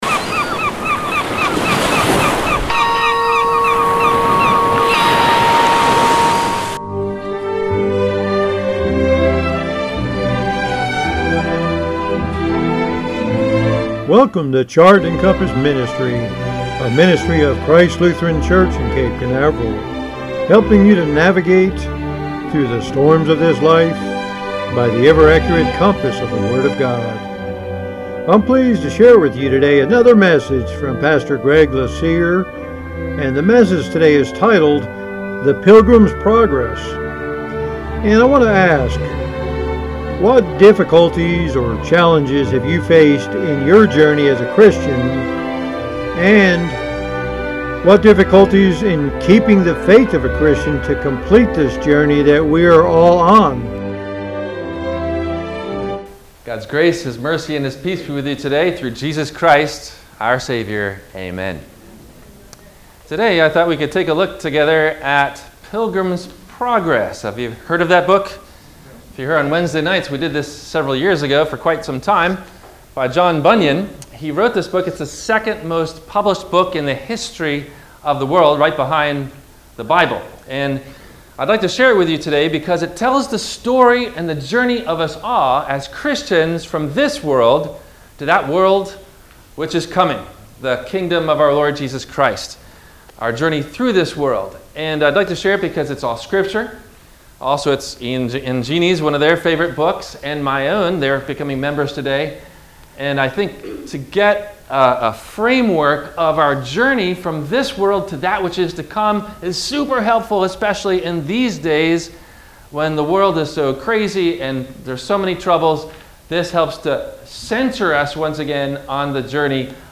The Pilgrim's Progress - Sermon - September 12, 2021 - Christ Lutheran Cape Canaveral